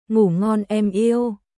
Ngủ ngon em yêuグー・ゴン・エム・イウおやすみ、愛してる（恋人向け）